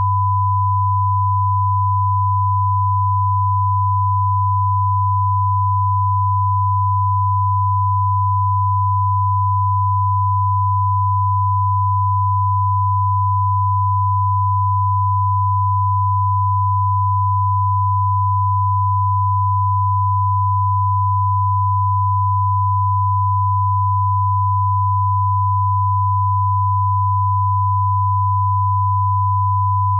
These test files contain -10dBFS 1kHz tone on the left channel and 100Hz tone on the right channel.
48k_2ch_float_tones.wav